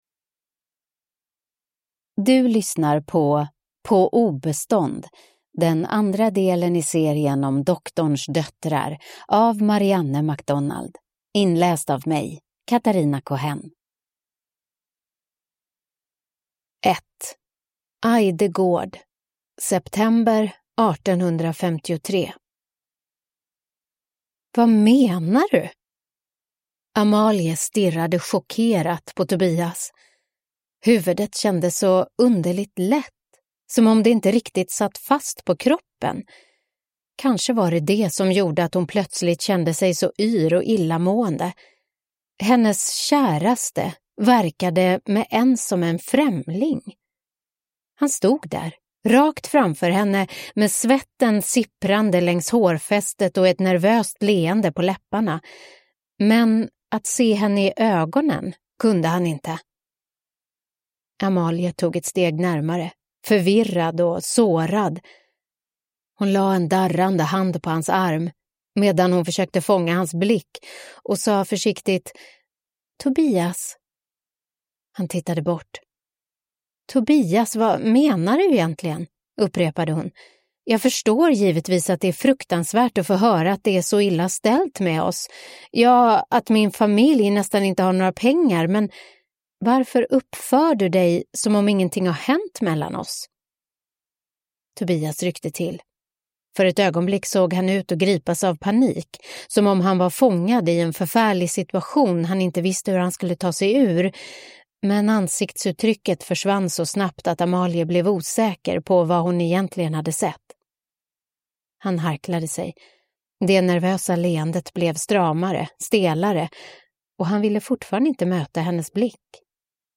På obestånd (ljudbok) av Marianne MacDonald